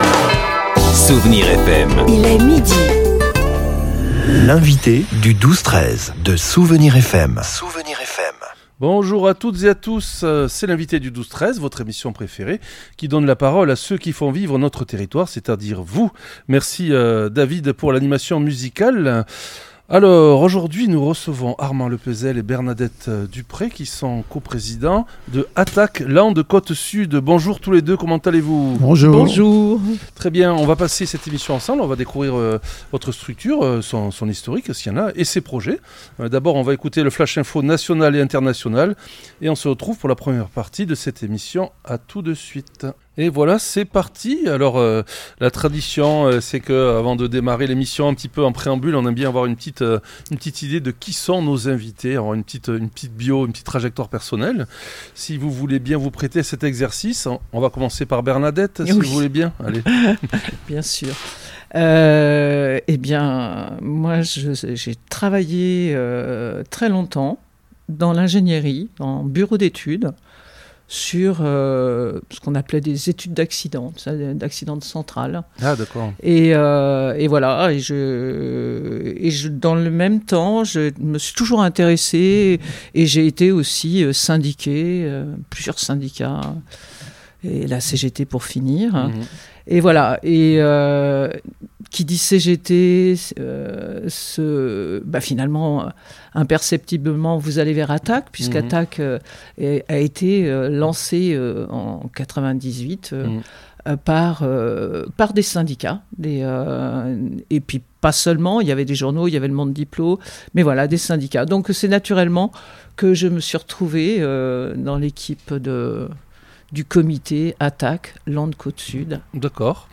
L'entretien a permis d'aborder le dossier brûlant de la LGV Bordeaux-Dax. Chiffres à l'appui, nos invités ont défendu la rénovation de la ligne existante, une option jugée moins coûteuse et moins destructrice pour nos forêts et terres agricoles landaises.